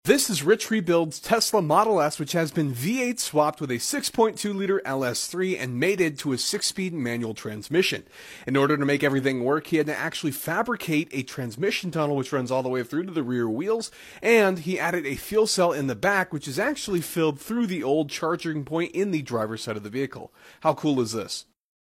V8 Swapped Tesla Model S Sound Effects Free Download